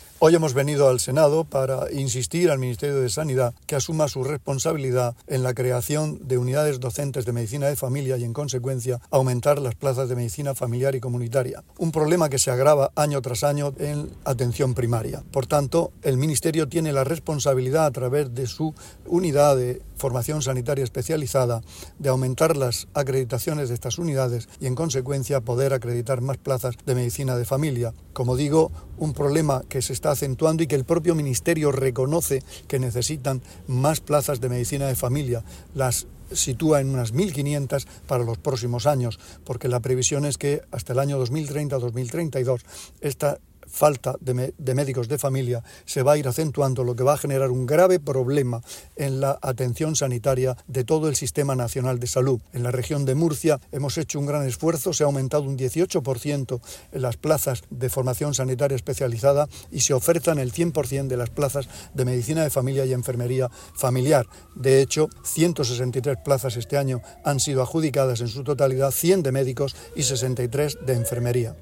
El consejero participó hoy en la Comisión General de las Comunidades Autónomas de la Cámara Alta que debatió sobre "la falta de facultativos en el Sistema Nacional de Salud"
Declaraciones del consejero de Salud, Juan José Pedreño.